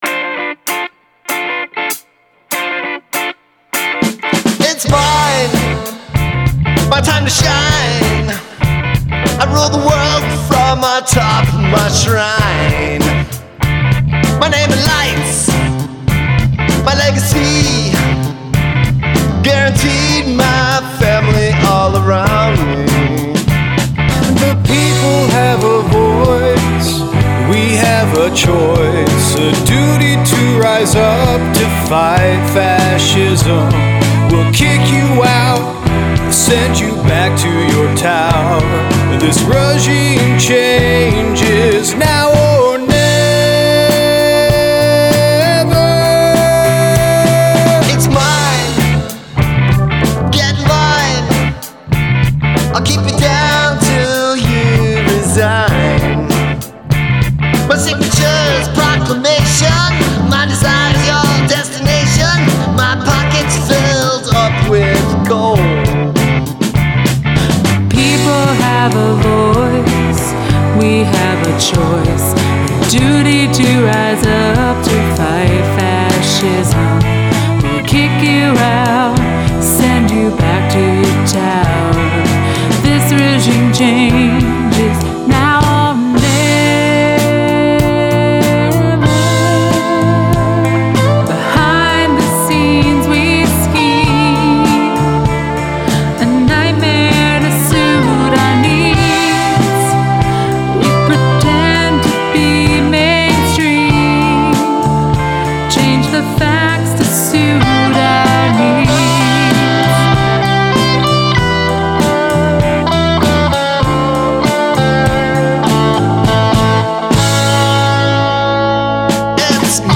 keys
vocals
All your vocalist did a fine job, I was very impressed.